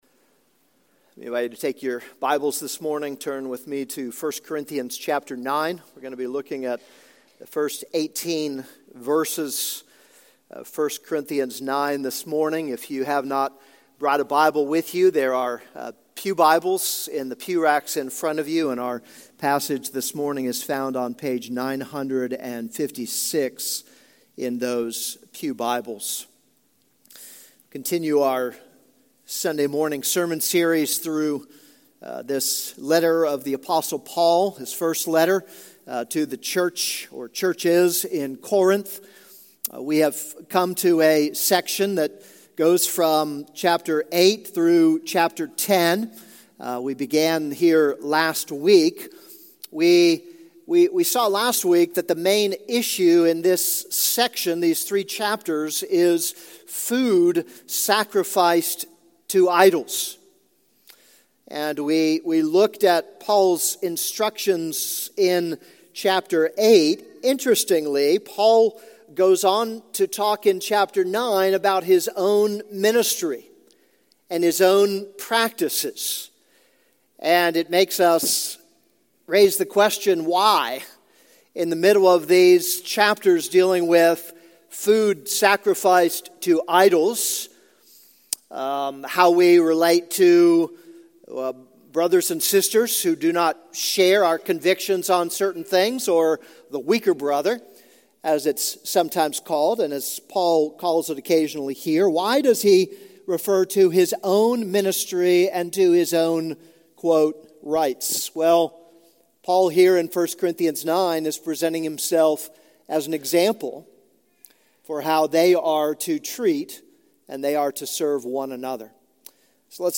This is a sermon on 1 Corinthians 9:1-18.